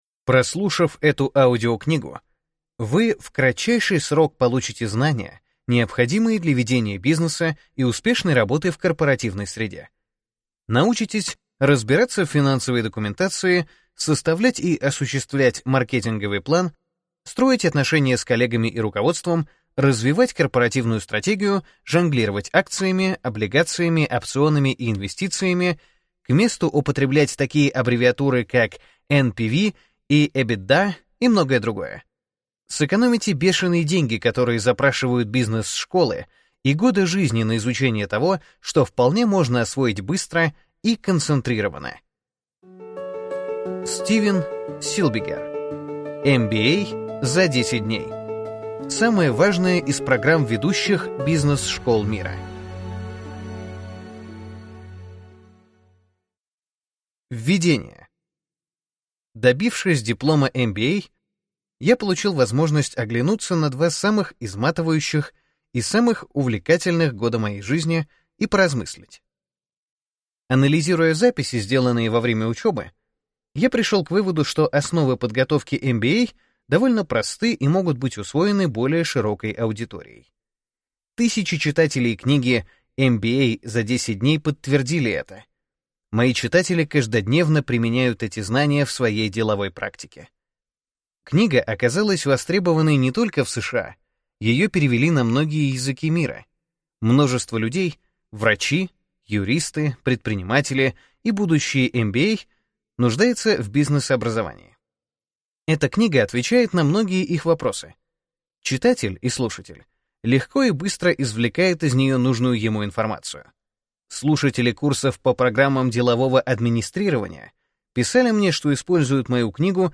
Аудиокнига МВА за 10 дней. Самое важное из программ ведущих бизнес-школ мира | Библиотека аудиокниг